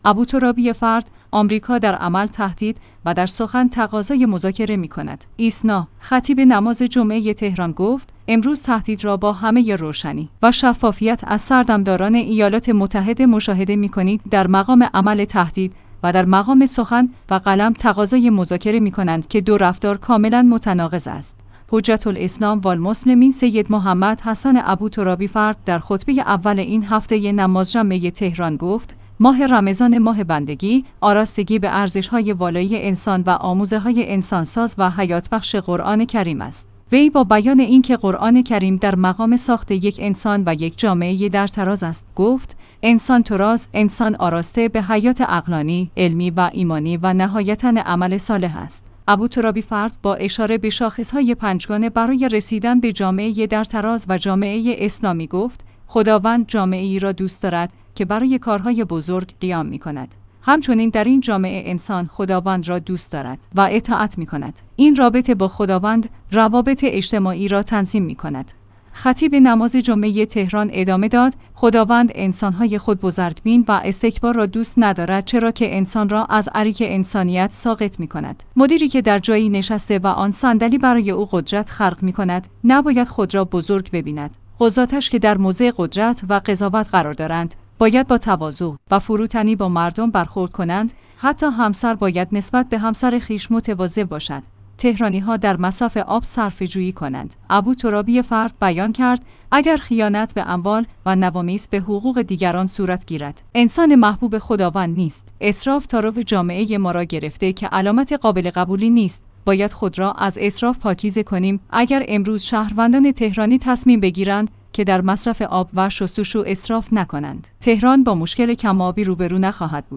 حجت الاسلام والمسلمین سیدمحمد حسن ابوترابی‌فرد در خطبه‌ اول این هفته نماز جمعه تهران گفت: ماه رمضان